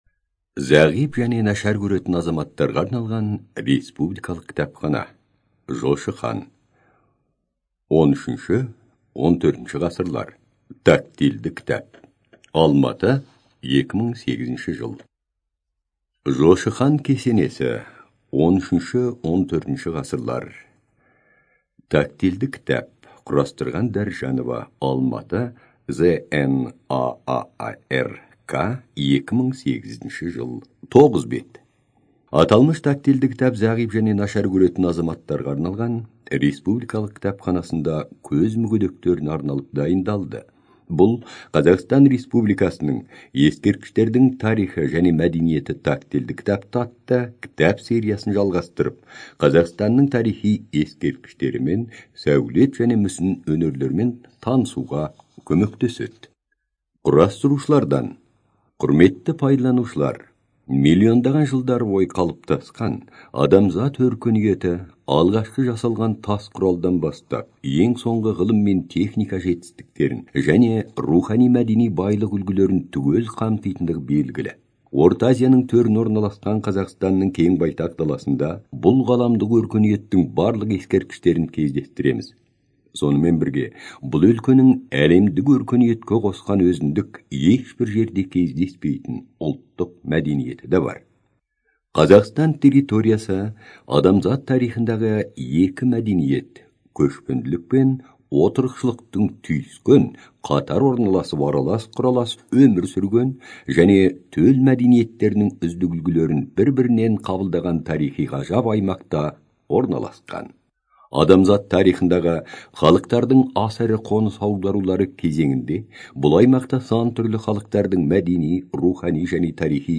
ЖанрАудиоэкскурсии и краеведение
Студия звукозаписиКазахская республиканская библиотека для незрячих и слабовидящих граждан